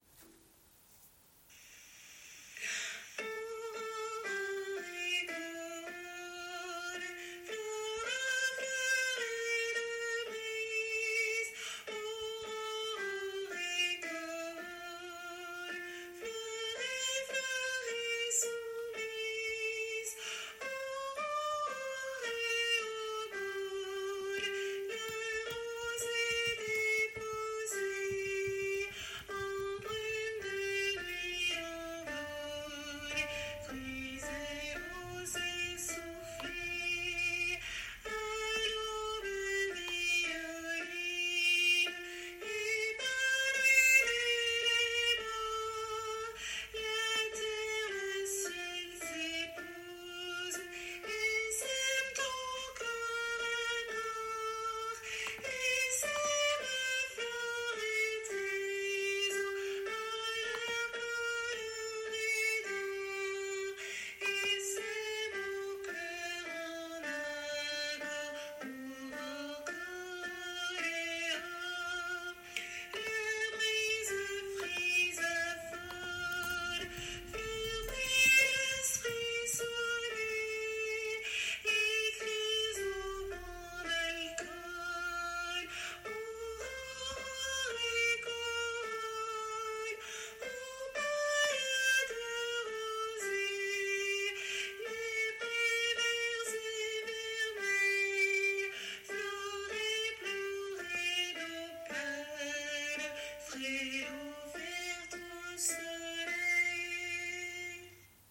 - Oeuvre pour choeur à 4 voix mixtes (SATB)
MP3 versions chantées
Soprano